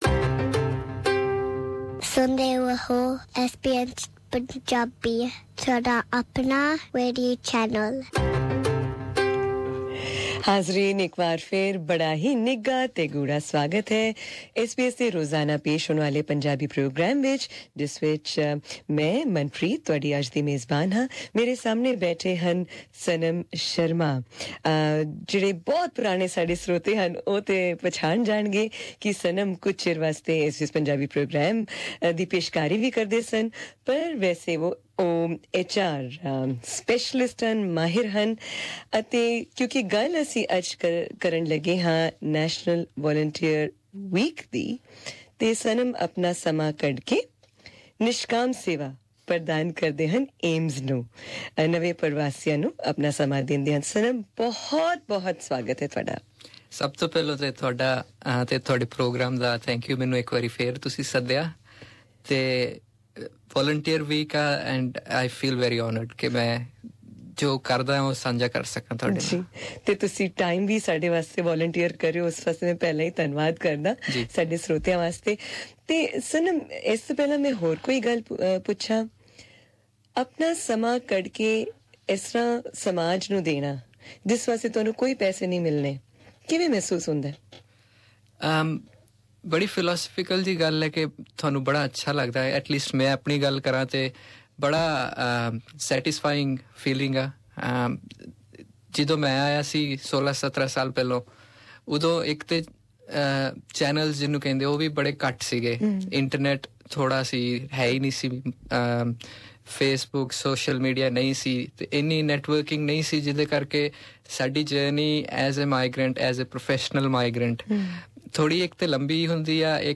To mark National Volunteer Week, we have brought you many special interviews this week, but none more special than this one.